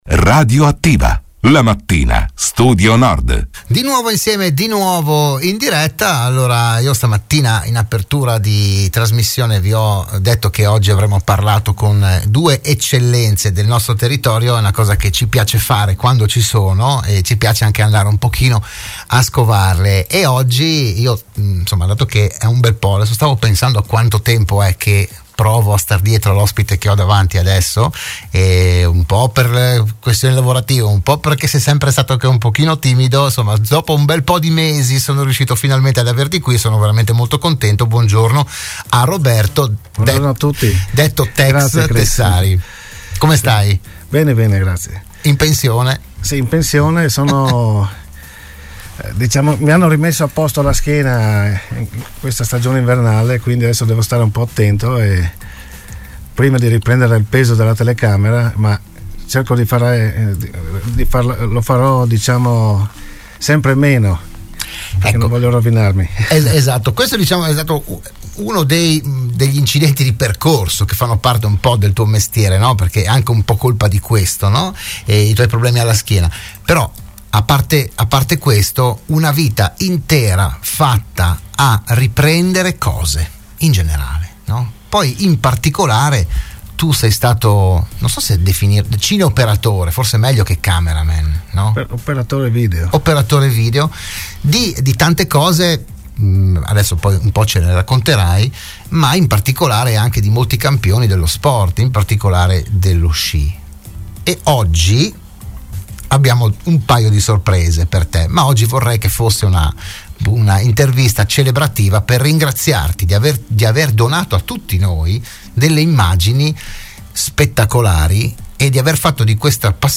E a omaggiarlo, al telefono, c’erano il grandissimo Alberto Tomba, che ha avuto un rapporto strettissimo con lui sin dai primi anni della sua straordinaria carriera, e l’opinionista di punta della R